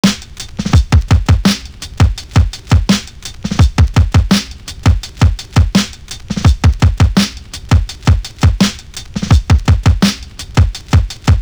Tlife Drum.wav